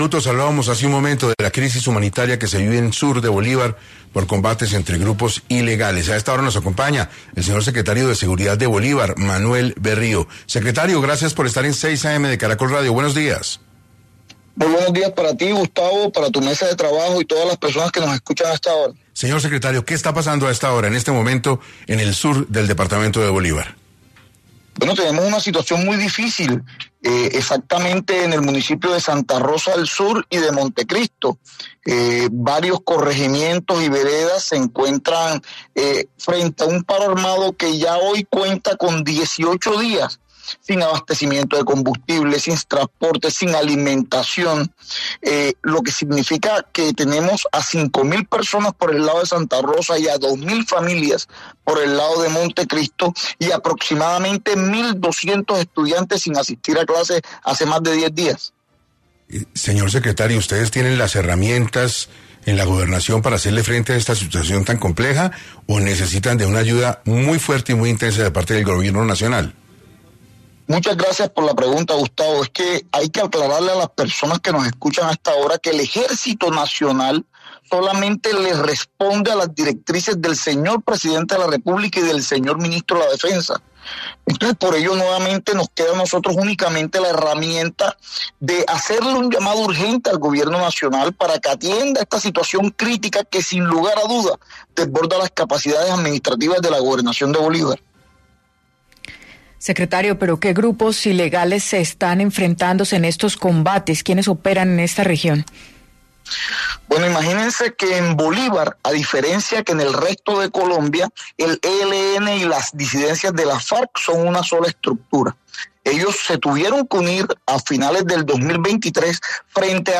Manuel Berrío, secretario de Seguridad de Bolívar, aseguró en 6AM que la Fuerza Pública no tiene órdenes de confrontar a los grupos armados, que tienen desbordada la violencia en la región.